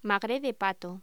Locución: Magret de pato
voz